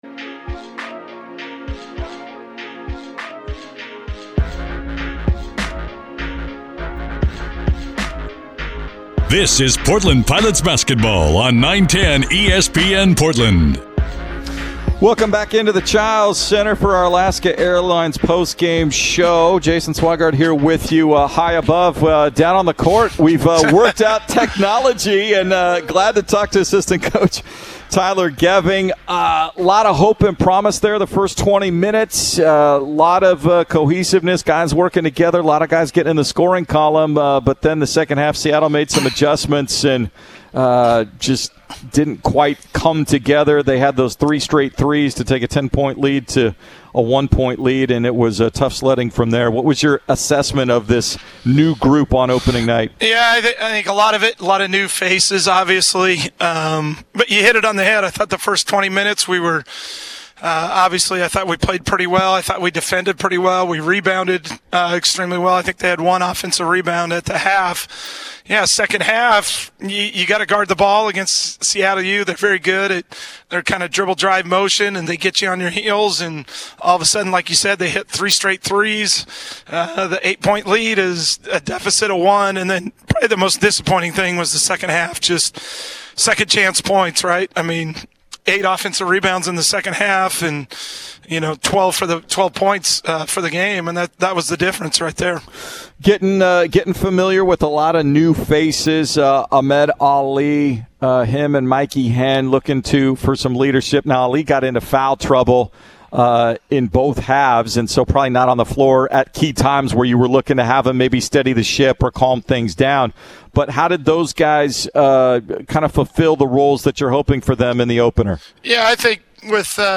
Men's Hoops Post-Game Interview vs. Seattle U